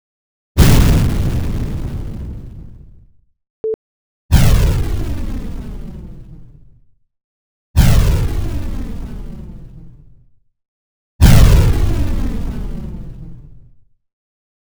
A rocket: /uploads/default/original/2X/c/c12871d839b6134ee075d410cc800a5f82995fdb.flac